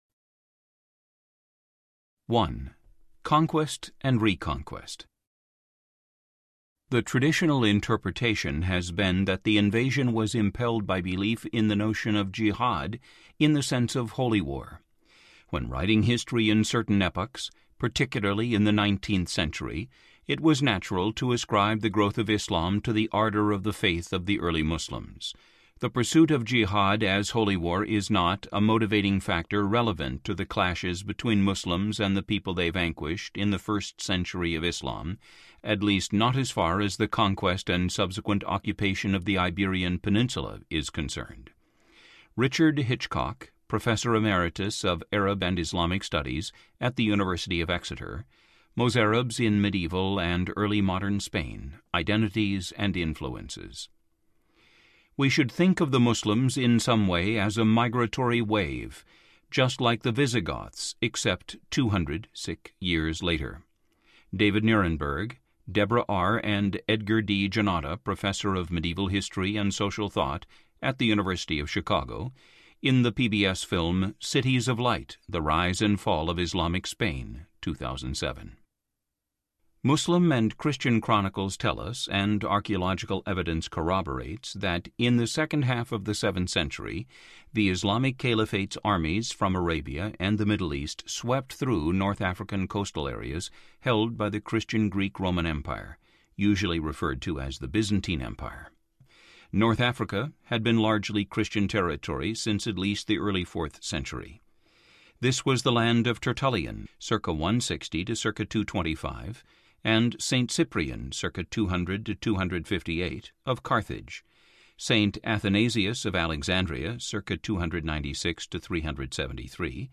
The Myth of the Andalusian Paradise Audiobook
Narrator